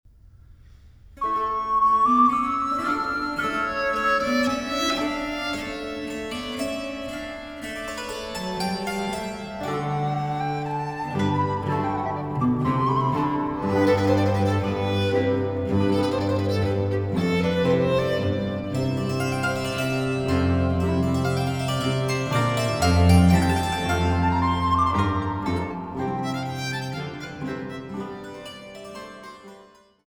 durch Flöten, Hackbrett und diverse Continuo-Instrumente